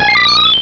Cri de Zarbi dans Pokémon Rubis et Saphir.